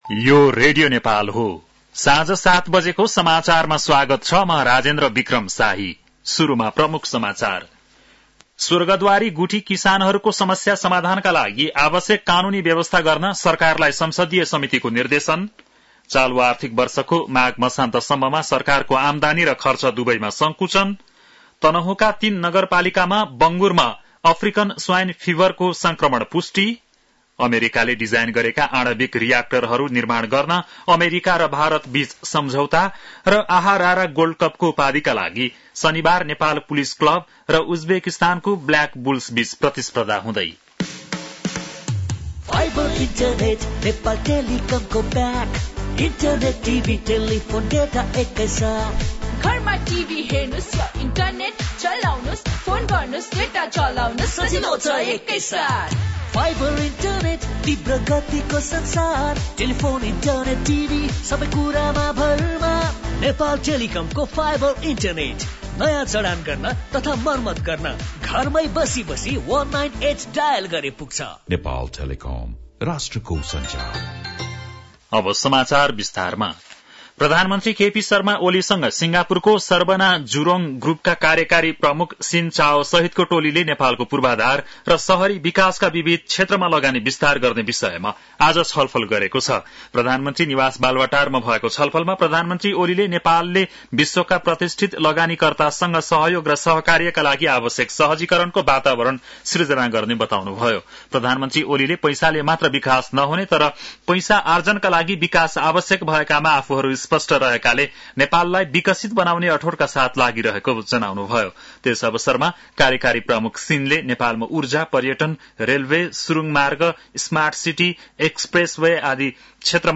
An online outlet of Nepal's national radio broadcaster
बेलुकी ७ बजेको नेपाली समाचार : ३ फागुन , २०८१